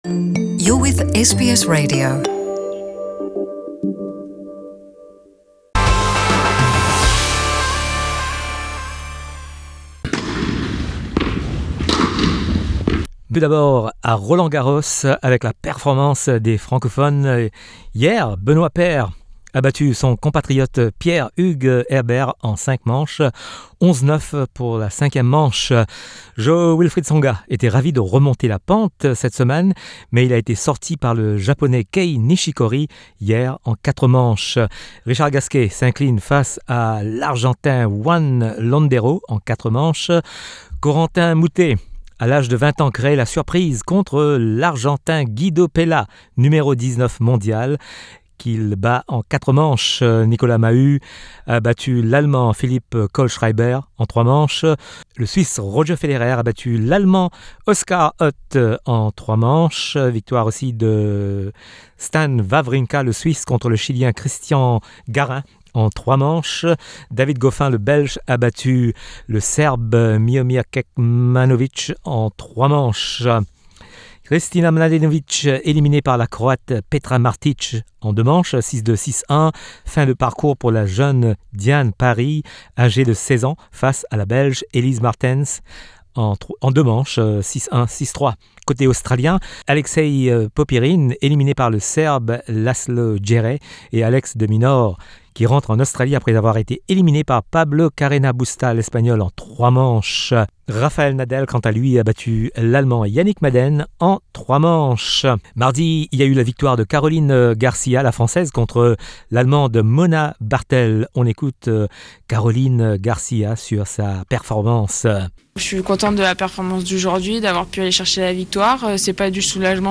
Retour sur l’actualité sportive avec les sonores de RFI.